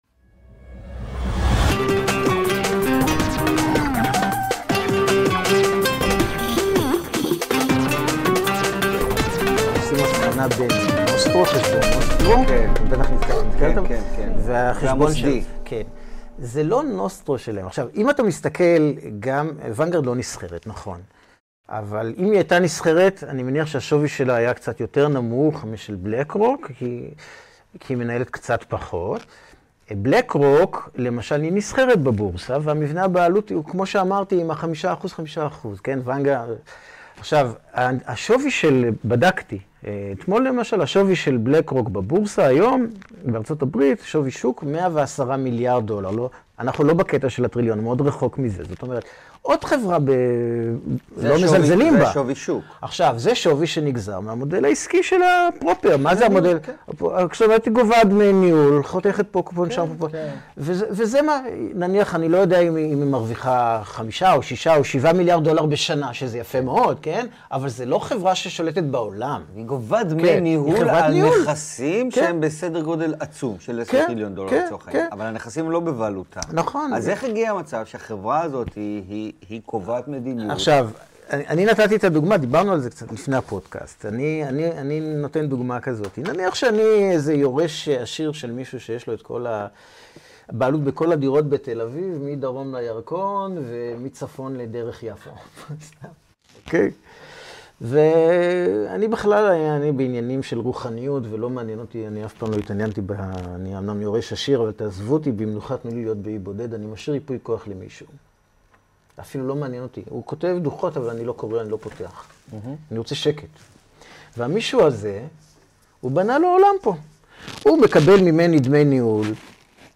מי שולט בתאגידים-מתוך הראיון המלא